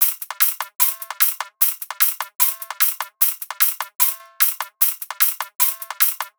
VR_top_loop_stomp_150.wav